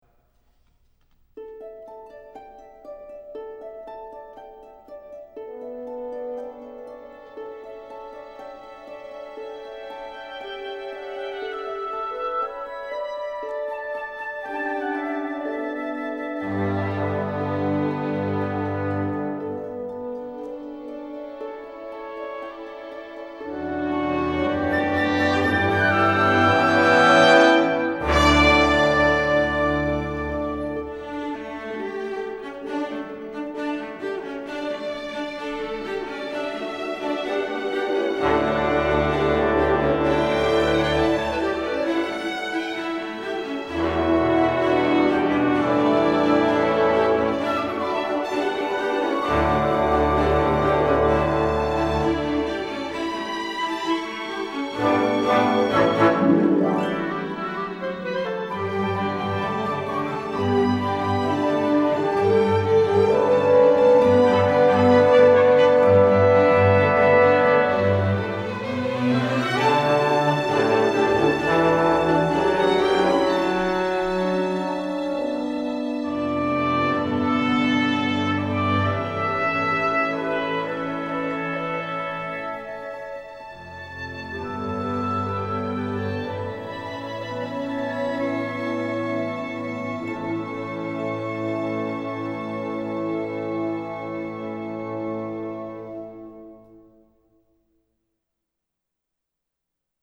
main title : adventure